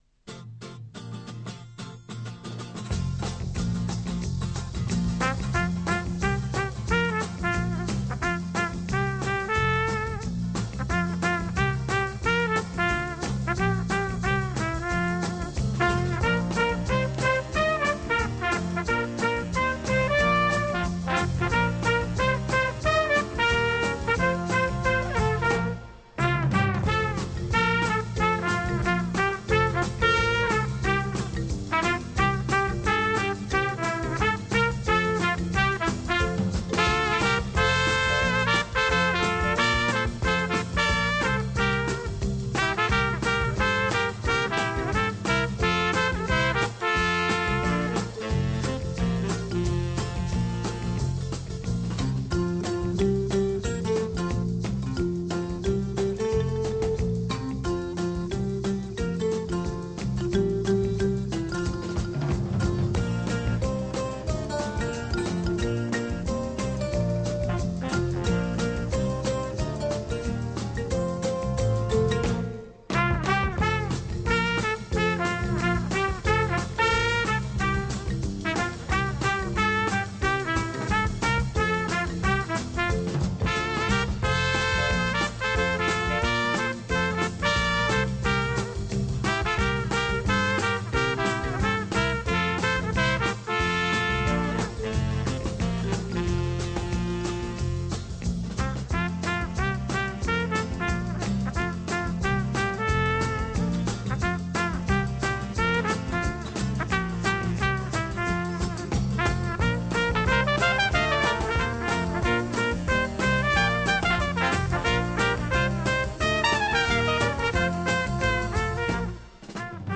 Mexican Flavoured